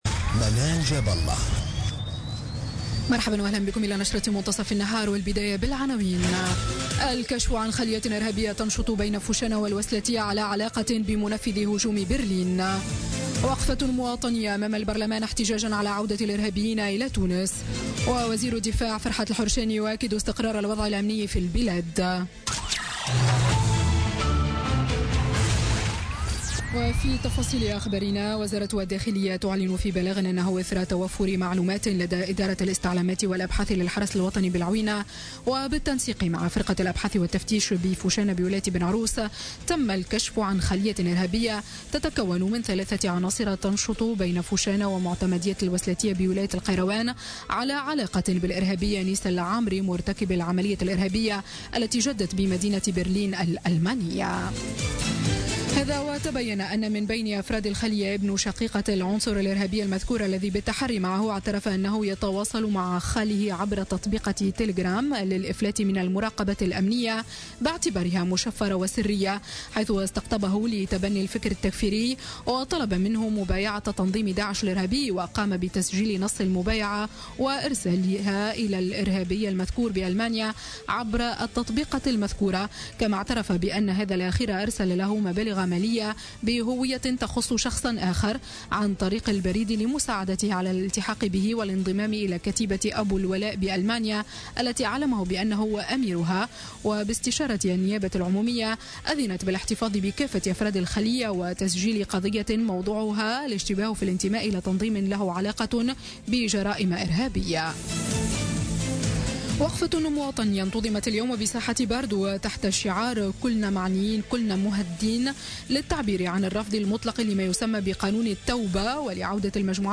نشرة أخبار منتصف النهار ليوم السبت 24 ديسمبر 2016